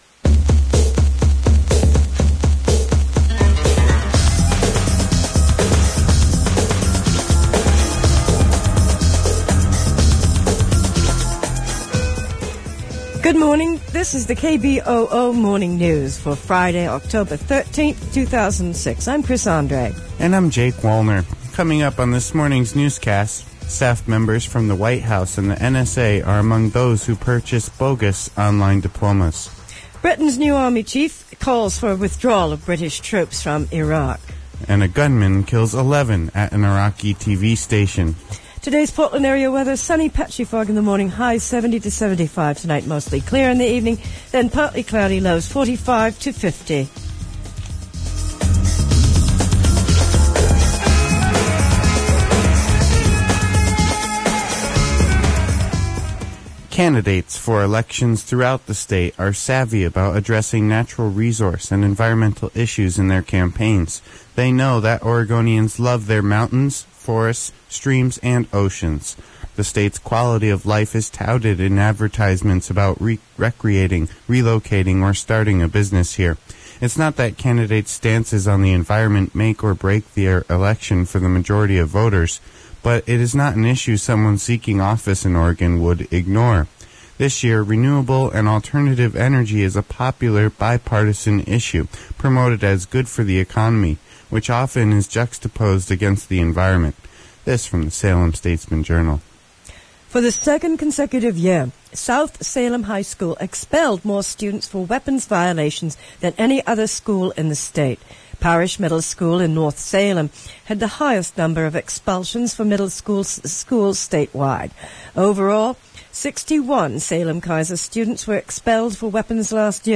Version 5: fri oct 13 th news and public affairs day, as broadcasts Description: 21min/hr of Membership Drive appeals have NOT been edited out of this version
Location Recorded: portland oregon